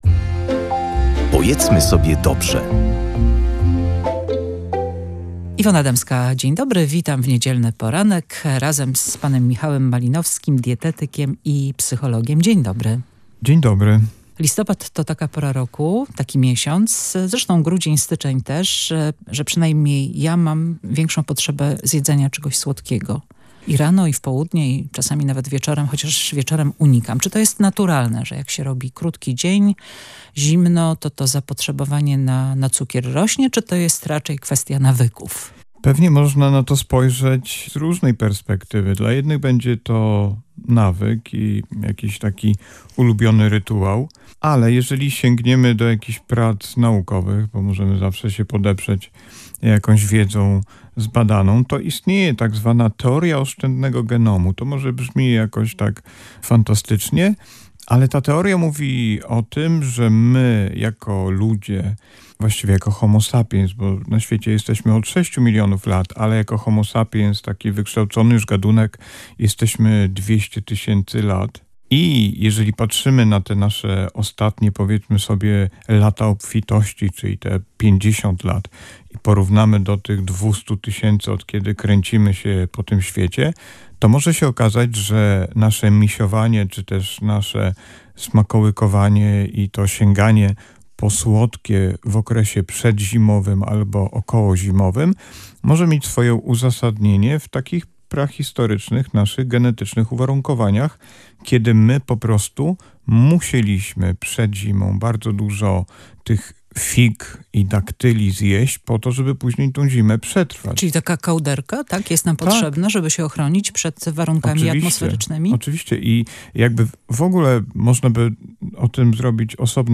Teoria oszczędnego genomu - Radio Gdańsk